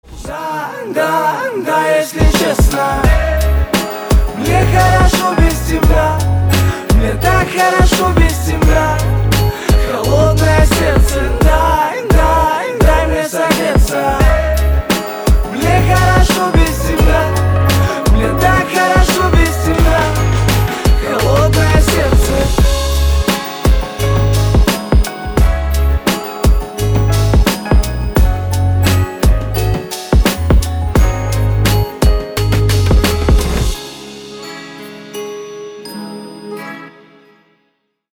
• Качество: 320, Stereo
мужской вокал
громкие
лирика
русский рэп